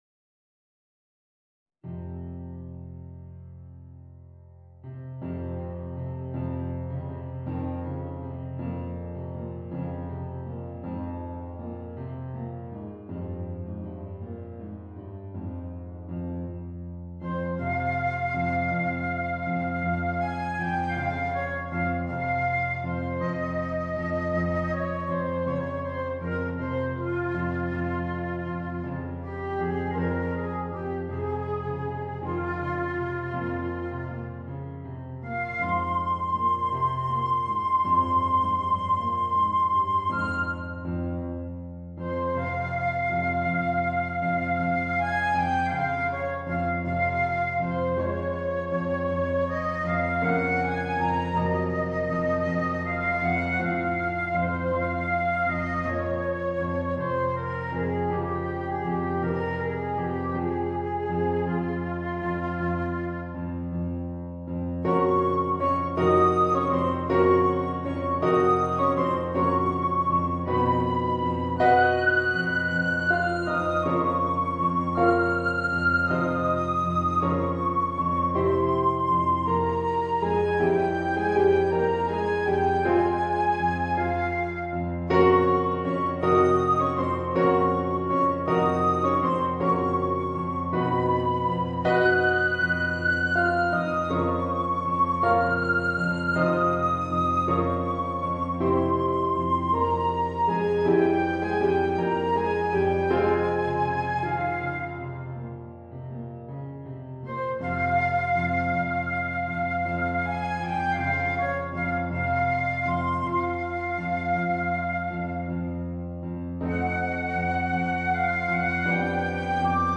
Voicing: Flute and Piano